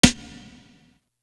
Legacy Snare.wav